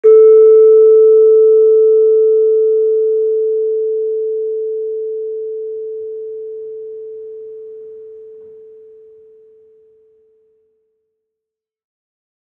Gamelan / Gender
Gender-2-A3-f.wav